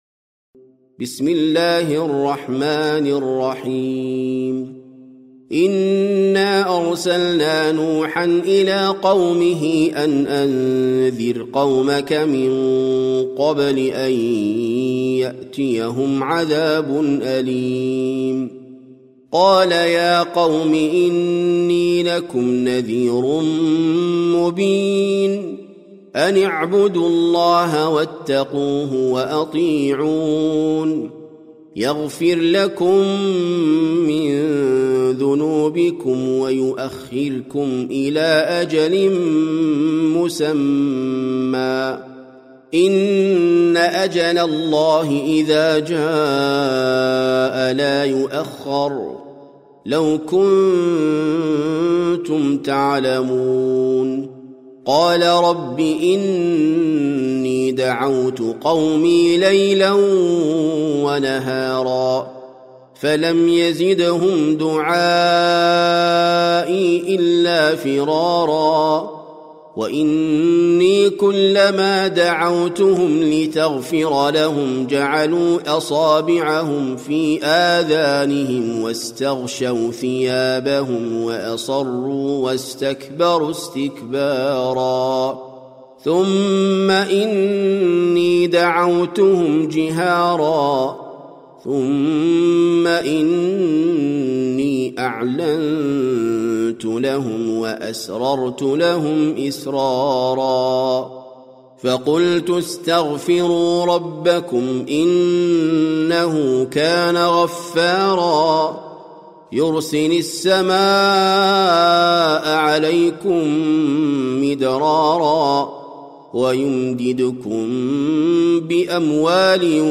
سورة نوح - المصحف المرتل (برواية حفص عن عاصم)